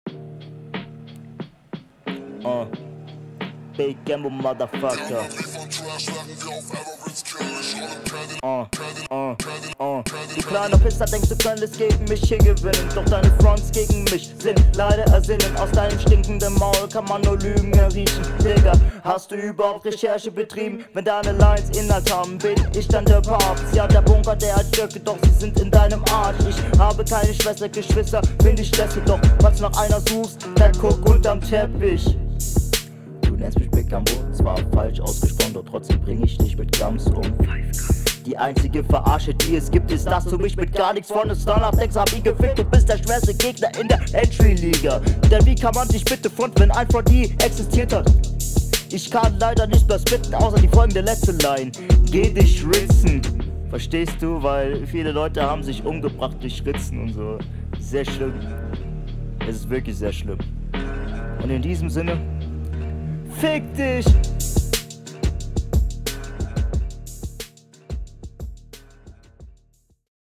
Einstieg sehr cool, fällt aber direkt auf, dass es nicht wirkluch gut gemischt ist und …
Flow: Dein Flow ist prinzipiell echt gut, aber ein ticken schlechter als der deines Gegners.